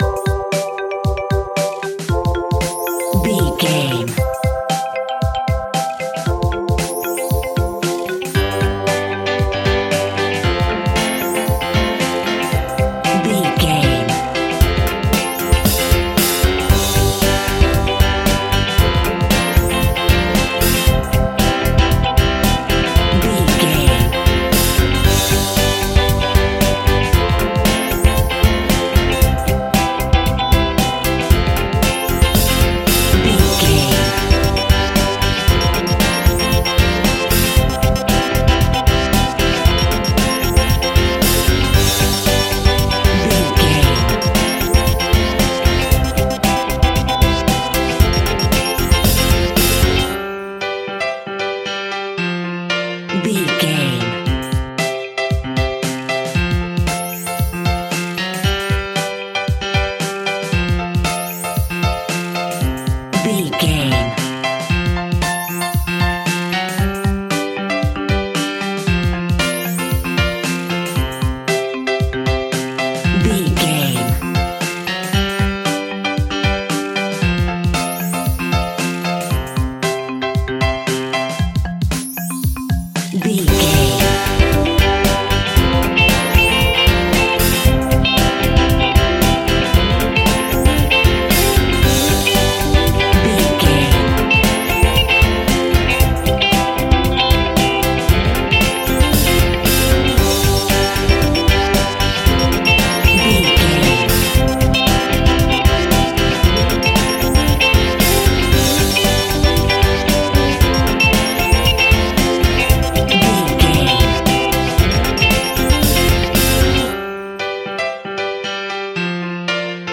Ionian/Major
hopeful
joyful
lively
bright
electric guitar
bass guitar
drums
piano
electric organ